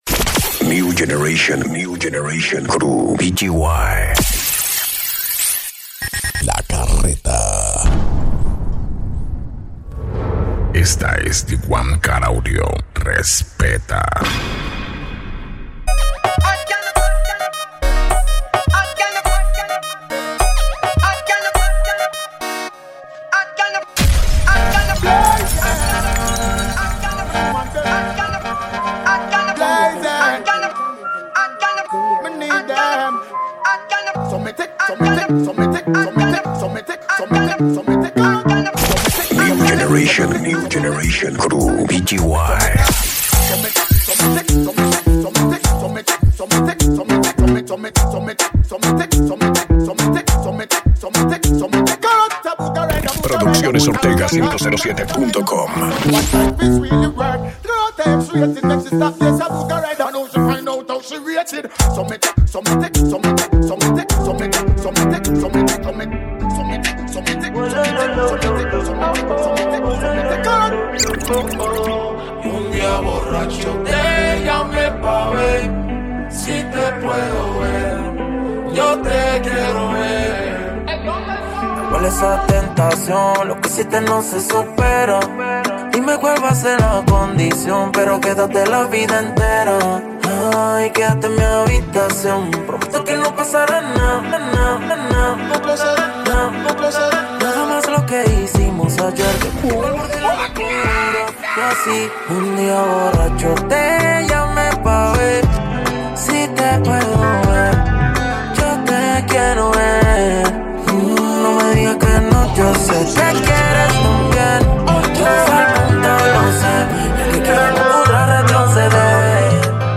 Mixes
Reggae